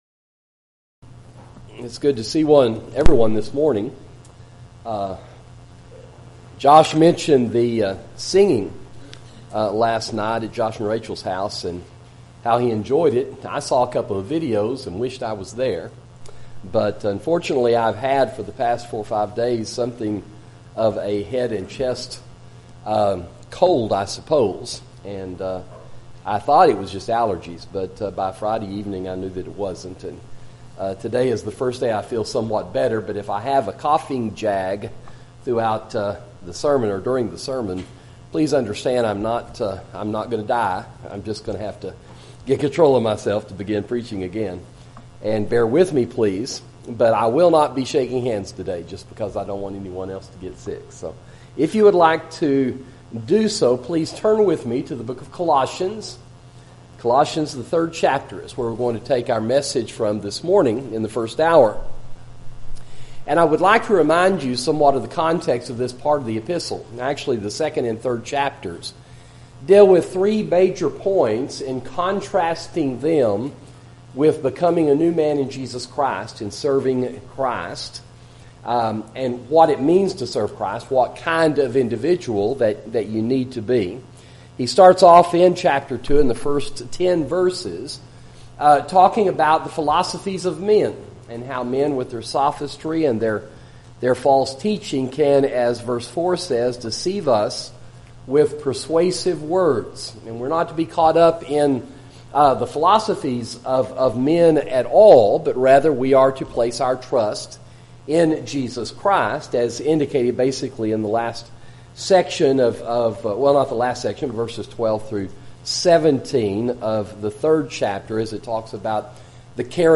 Sermon: Reject the World – Sound Teaching